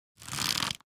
Minecraft Version Minecraft Version snapshot Latest Release | Latest Snapshot snapshot / assets / minecraft / sounds / item / crossbow / quick_charge / quick2_3.ogg Compare With Compare With Latest Release | Latest Snapshot